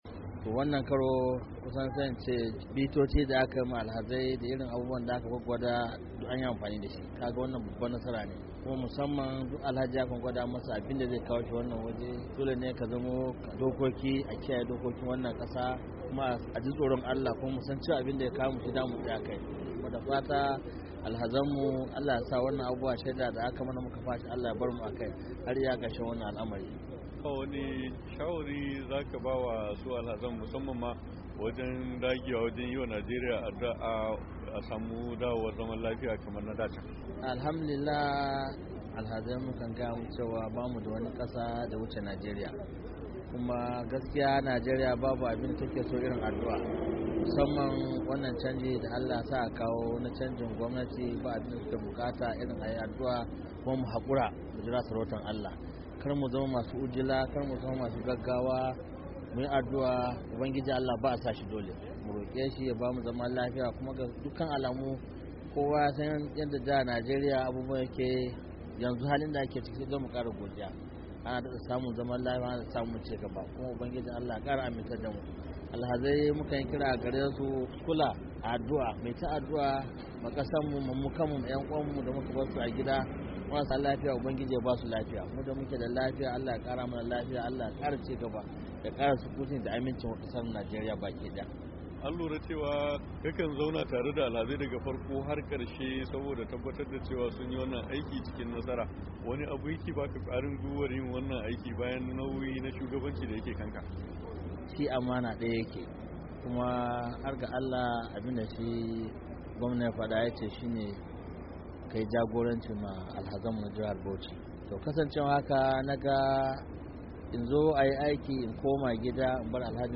Biyo bayan kammala aikin hajji mai martaba sarkin Bauchi ya zanta da Muryar Amurka akan nasarorin da aka samu a aikin hajin bana